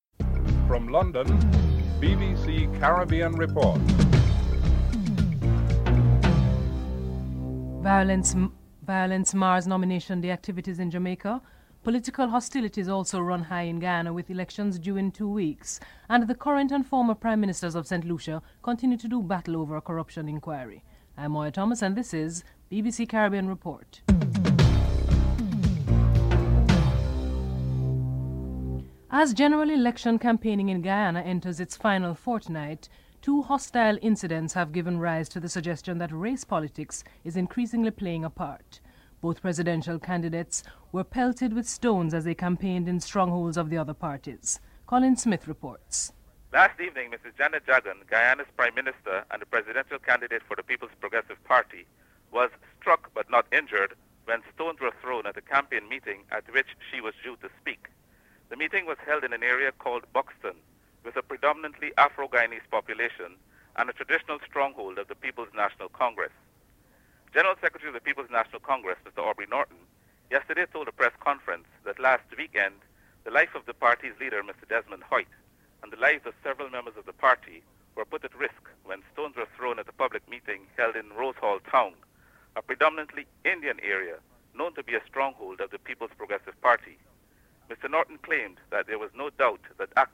Prime Minister Kenny Anthony is interviewed (05:04-08:41)